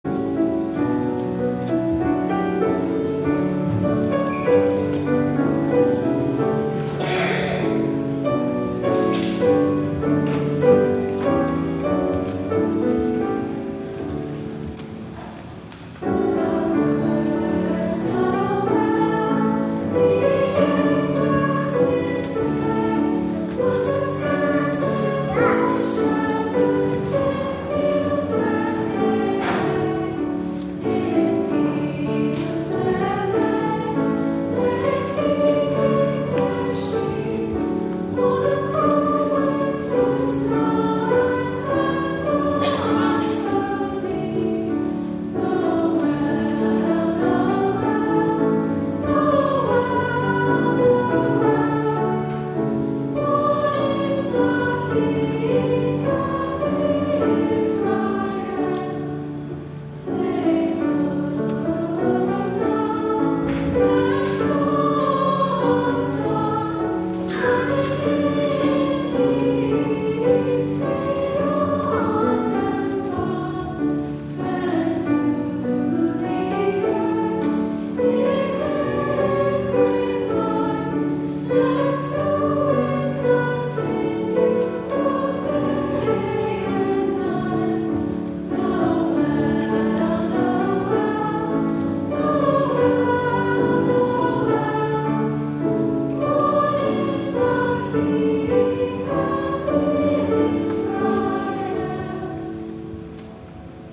11 A.M. WORSHIP
The Youth Choir